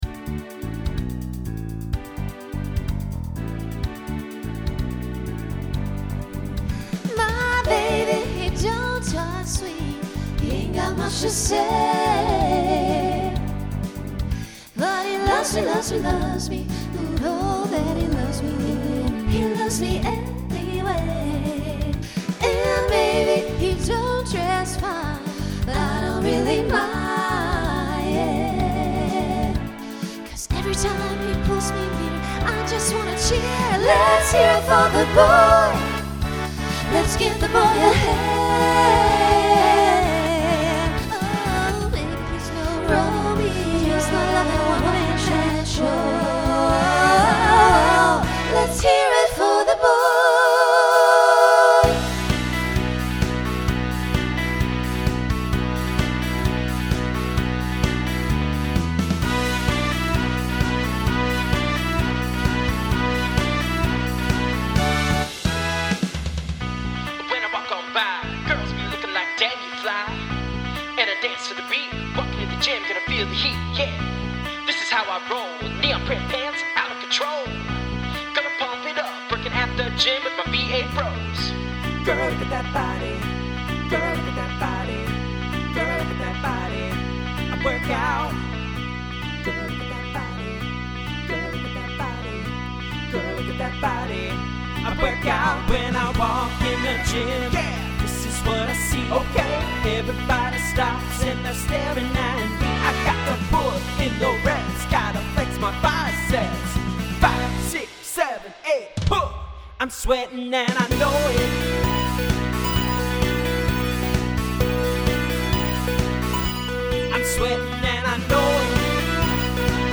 SSA Trio followed by TTB
Pop/Dance , Rock
Transition Voicing Mixed